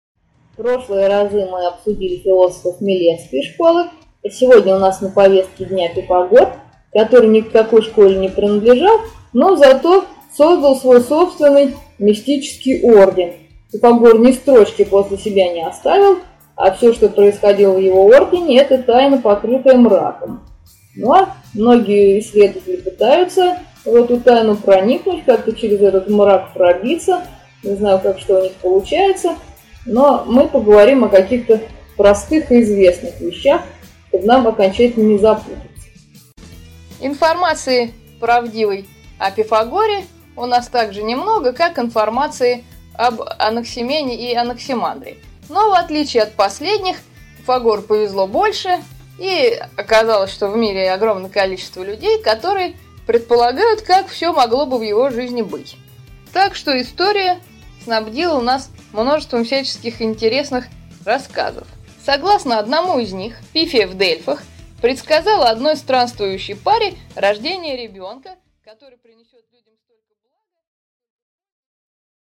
Аудиокнига 4. Древнегреческие философы. Пифагор | Библиотека аудиокниг